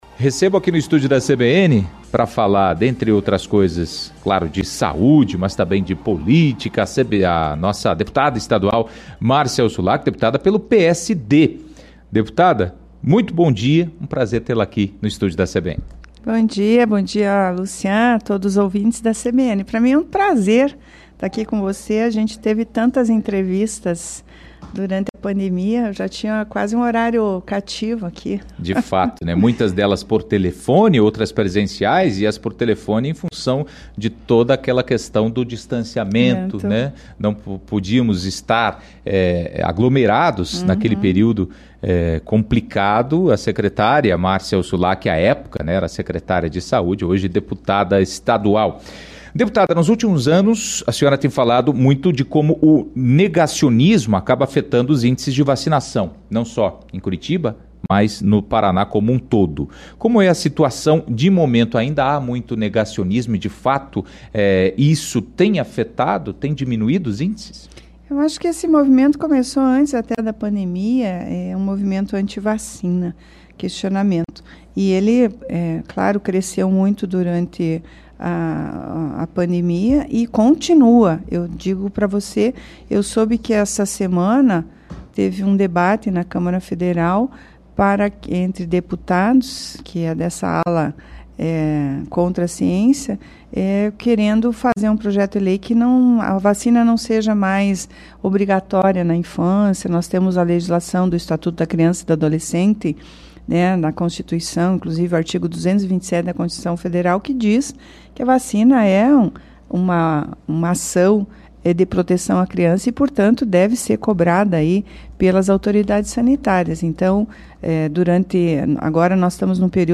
Em entrevista ao CBN Curitiba 1ª Edição desta quinta-feira (28), a deputada estadual Márcia Huçulak (PSD), falou sobre como o negacionismo tem afetado os índices de vacinação em Curitiba e no Paraná. A parlamentar, que foi secretária municipal de saúde da capital, falou ainda do programa de atenção a pessoas com problemas com álcool e sobre apoios políticos nas eleições municipais deste ano.